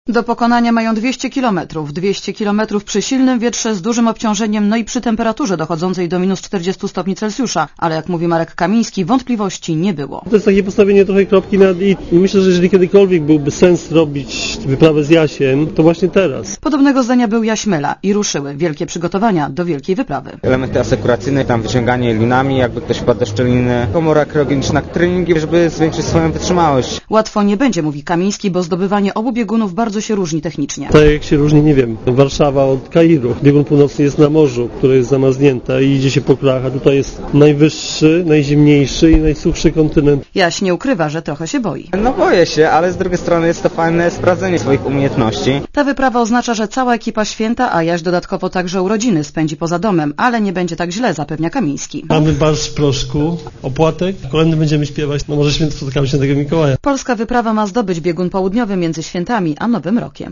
Relacja reportera Radia ZET
Czy wszystko się uda, zależy zwłaszcza od natury. Obawiamy się pogody i... nas samych, jak się zachowamy w ekstremalnych warunkach - powiedział na wtorkowej konferencji prasowej Marek Kamiński.